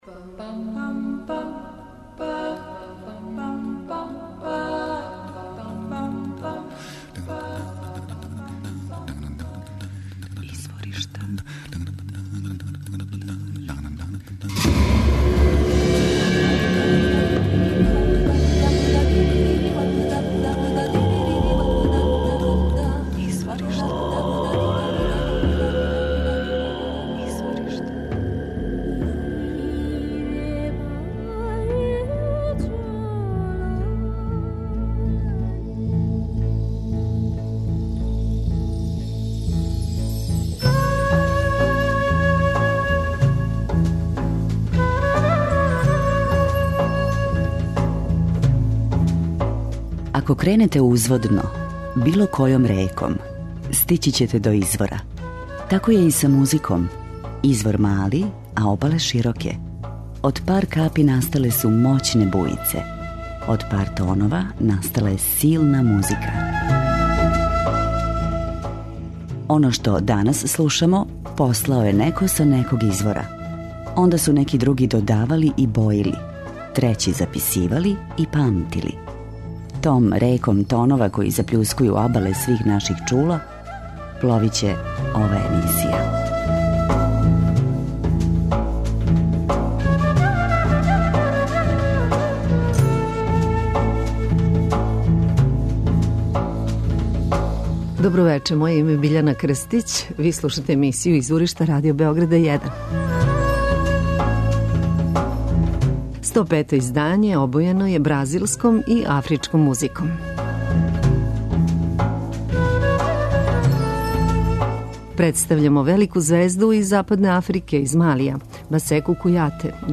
Ово 105. издање емисије 'Изворишта', обојено је бразилском музиком. Bossa nova је музички стил који је настао из sambe, а популаризовали су га Antonio Carlos Jobim, Vinicius de Moraes и Joao Gilberto.